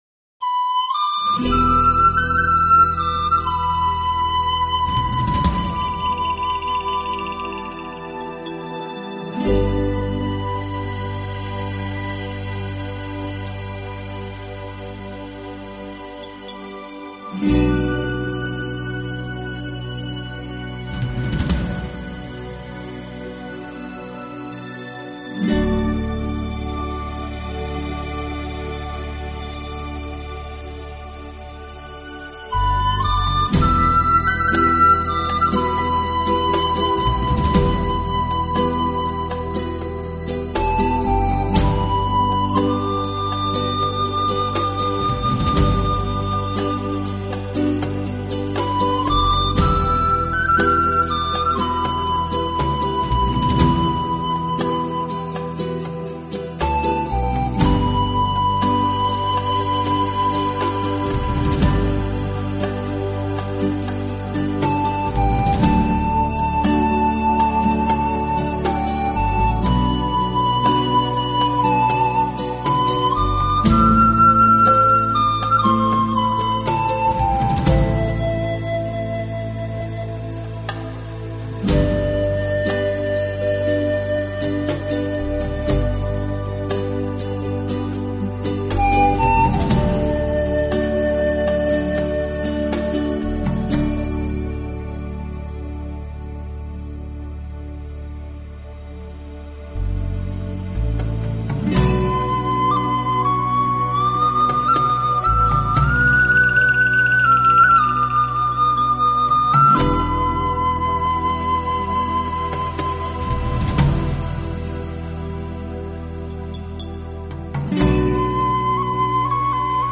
南美风情，南美音乐。